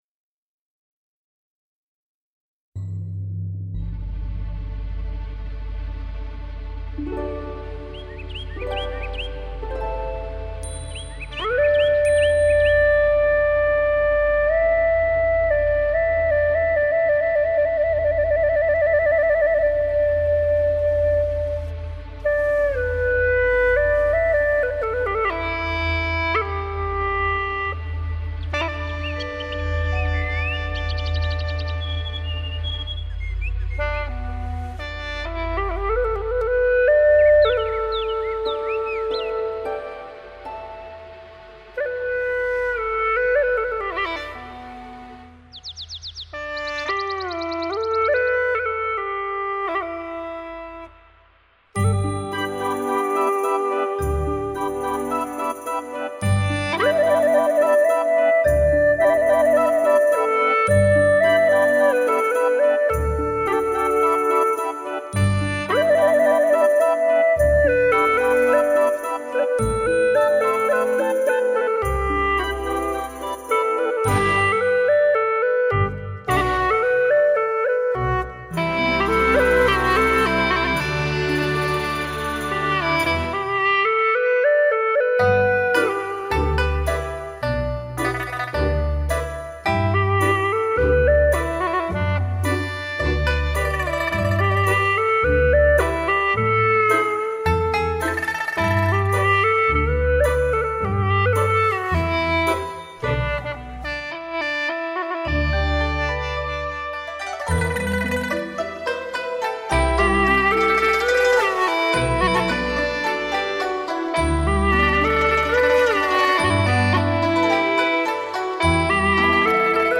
调式 : G